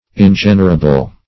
Search Result for " ingenerable" : The Collaborative International Dictionary of English v.0.48: Ingenerable \In*gen"er*a*ble\, a. [Pref. in- not + generable: cf. F. ingenerable.] Incapable of being engendered or produced; original.